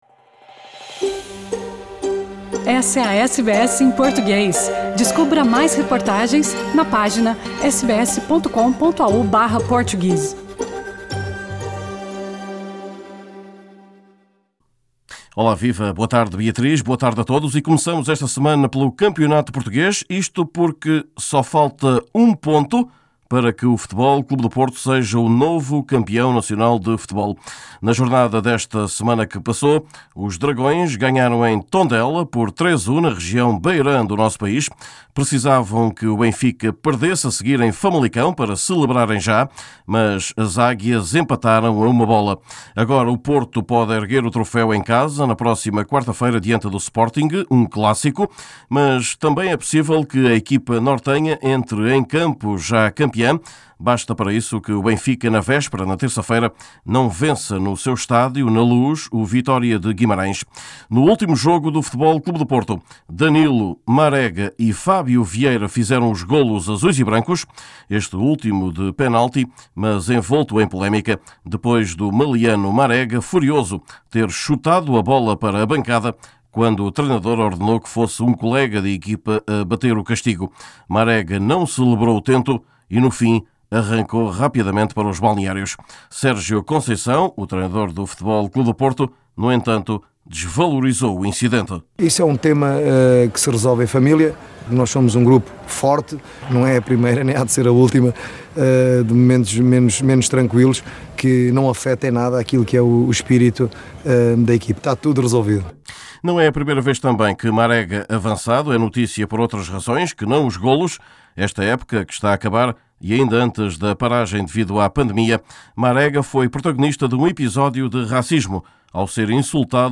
Neste boletim escutamos o mais do que provável técnico vencedor.